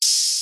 Open Hats
OH 1.wav